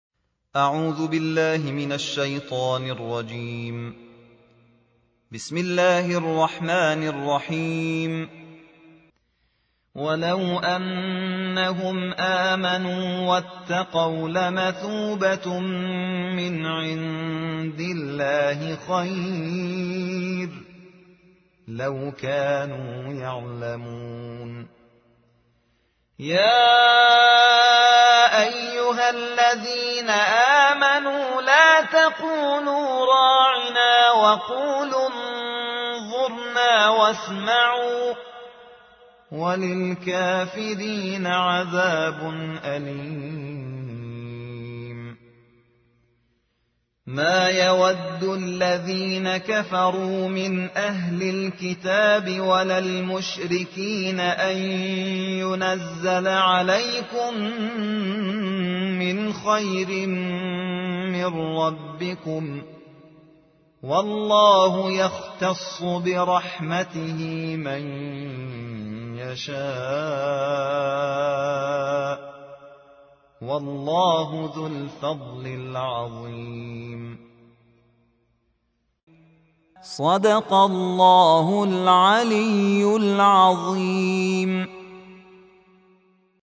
قرائت شبانه